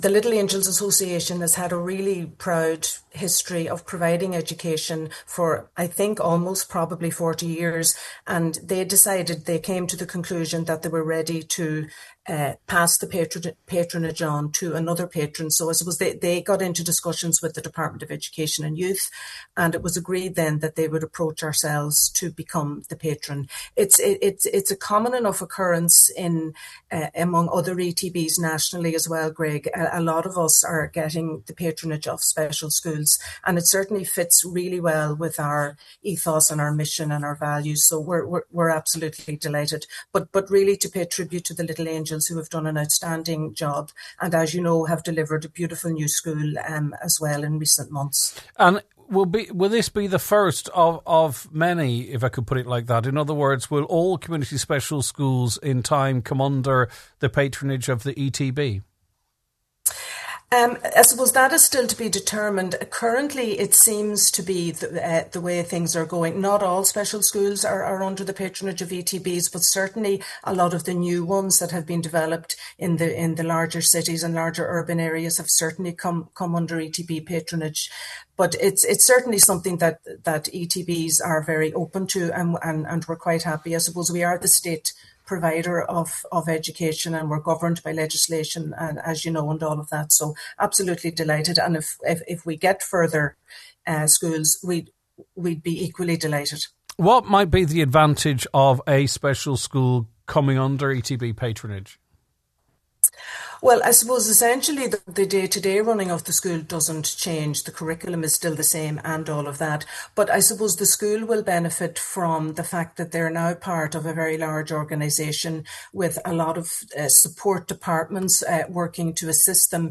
Highland Radio